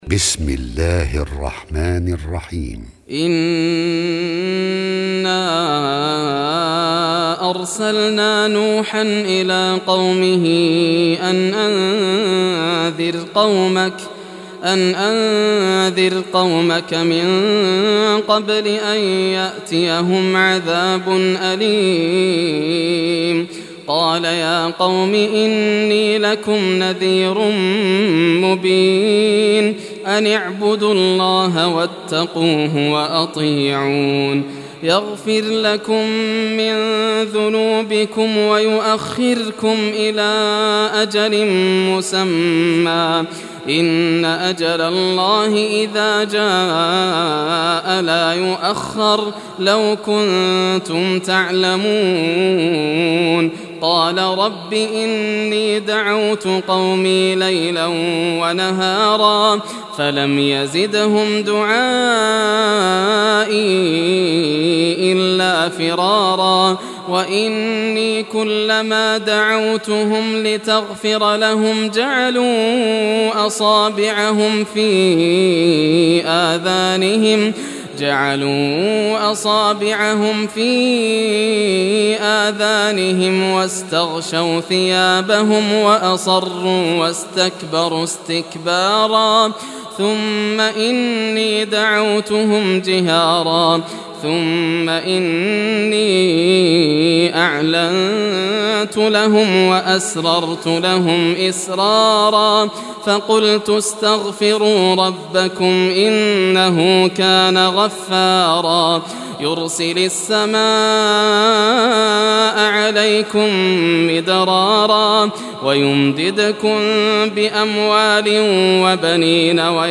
Surah Nuh Recitation by Yasser al Dosari
Surah Nuh, listen or play online mp3 tilawat / recitation in Arabic in the beautiful voice of Sheikh Yasser al Dosari.